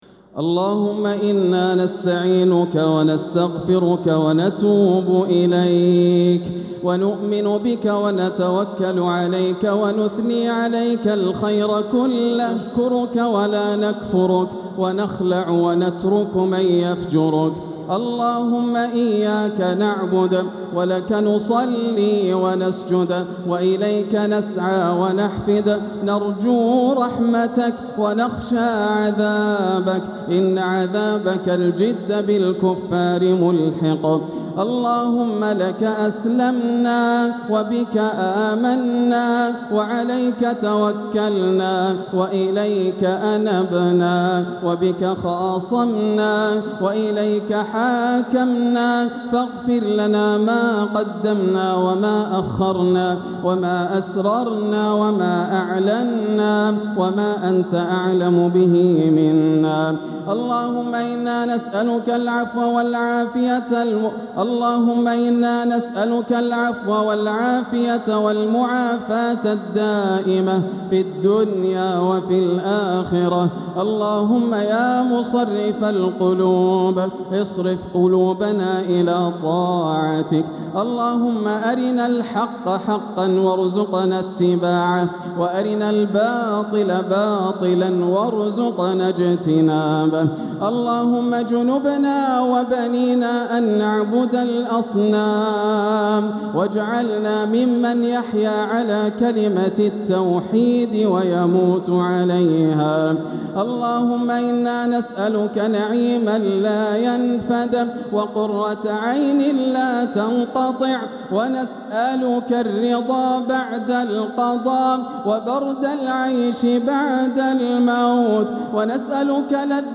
دعاء القنوت ليلة 17 رمضان 1446هـ > الأدعية > رمضان 1446 هـ > التراويح - تلاوات ياسر الدوسري